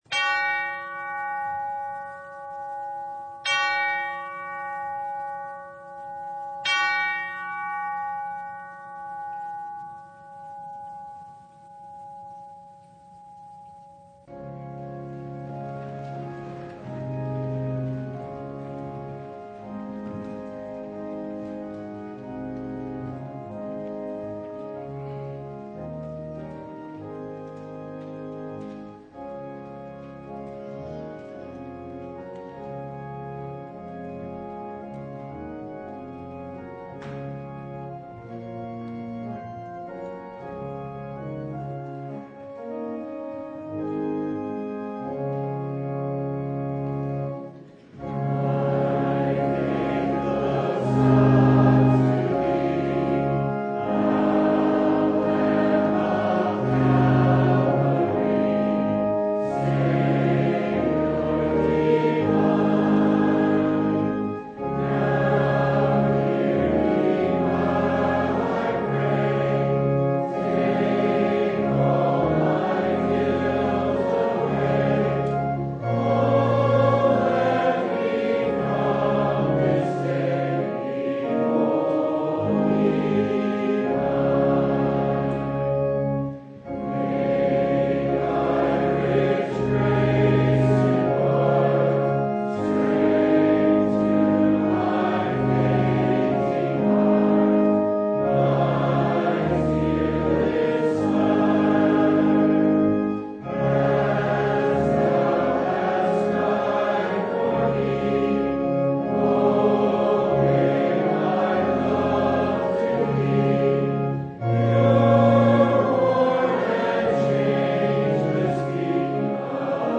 John 6:22-35 Service Type: Sunday “You are seeking Me